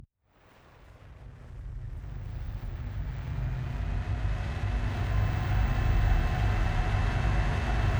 pgs/Assets/Audio/Sci-Fi Sounds/Mechanical/Engine 7 Start.wav at master
Engine 7 Start.wav